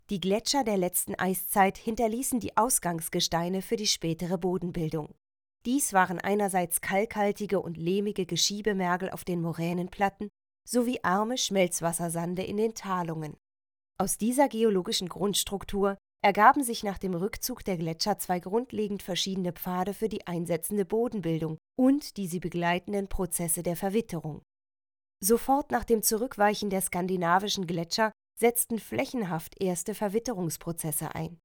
sehr variabel, hell, fein, zart, markant
Mittel minus (25-45)
Eigene Sprecherkabine
Audioguide
Audioguide, Comment (Kommentar), Doku, Off, Overlay